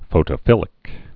(fōtə-fĭlĭk) also pho·toph·i·lous (fō-tŏfə-ləs)